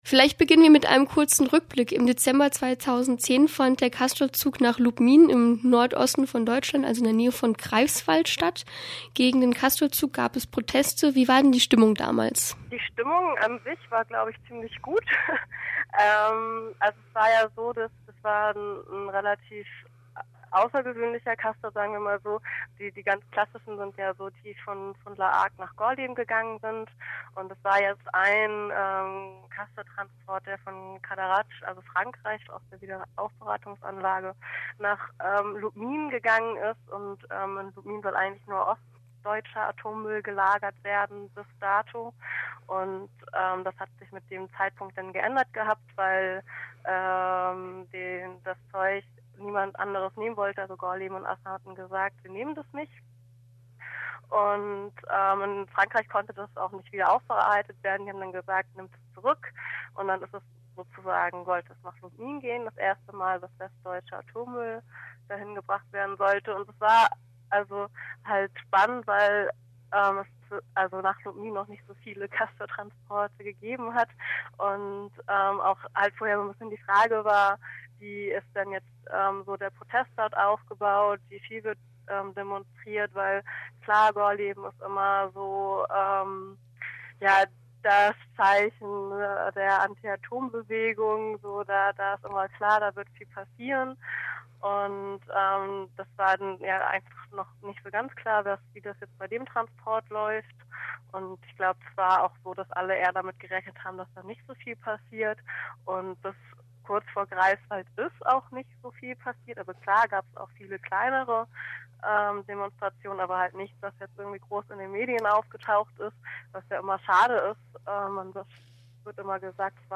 Um auf die Castor-Transporte aufmerksam zu machen, waren zahlreiche AktivsitInnen damals vor Ort um durch diverse Aktionen ihren Protest zu bekunden. 2 Aktivistinnen von Robin Wood sind mittlerweile angeklagt und der Prozess beginnt am Monatg (07.05.2012) im Amtsgericht in Greifswald. RDL sprach mit einer der Angeklagten.